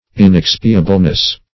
Inexpiableness \In*ex"pi*a*ble*ness\, n. Quality of being inexpiable.